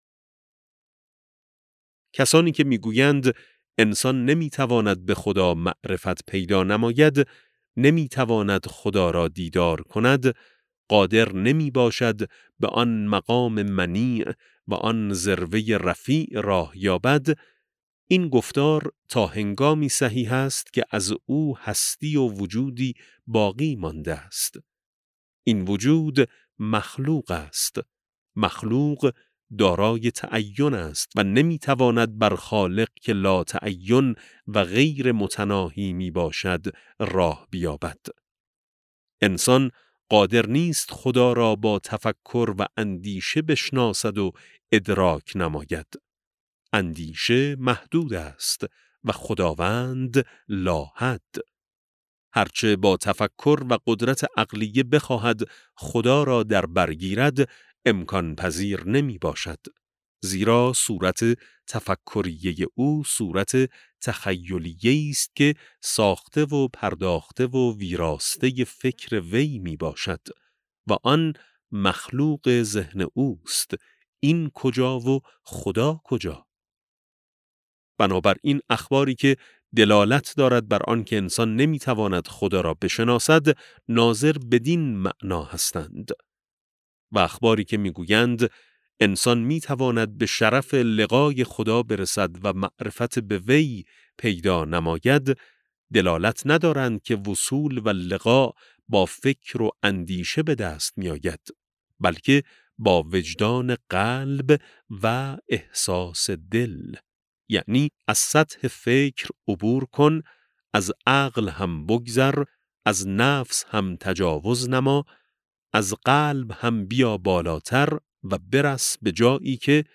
کتاب صوتی اللَه شناسی ج1 ( 20 تعداد فایل ها ) | علامه طهرانی | مکتب وحی